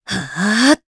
Aselica-Vox_Casting3_jp.wav